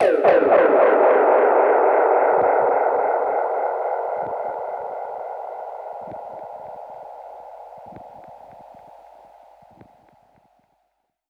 Index of /musicradar/dub-percussion-samples/85bpm
DPFX_PercHit_A_85-05.wav